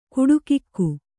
♪ kuḍukikku